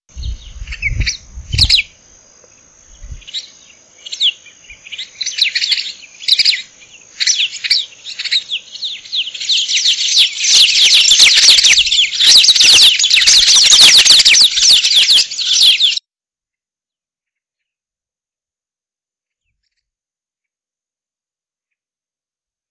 Pseudoleistes virescens - Pecho amarillo
pechoamarillo.wav